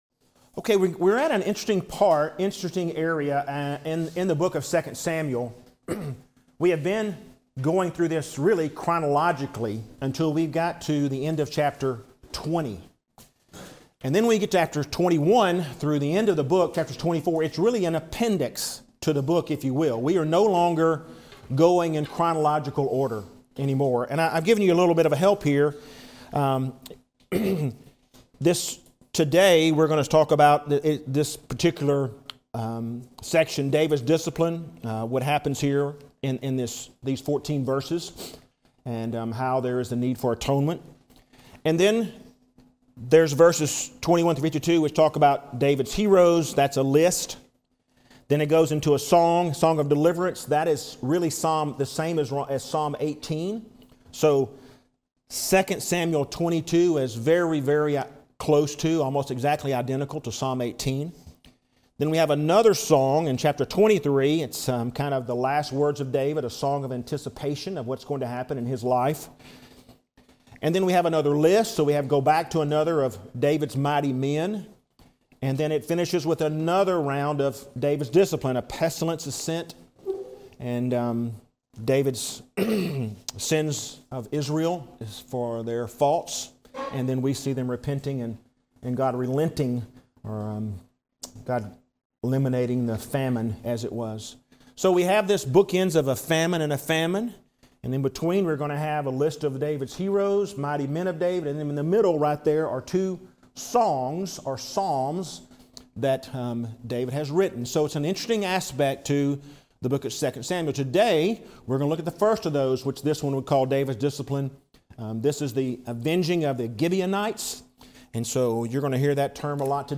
David's Discipline | SermonAudio Broadcaster is Live View the Live Stream Share this sermon Disabled by adblocker Copy URL Copied!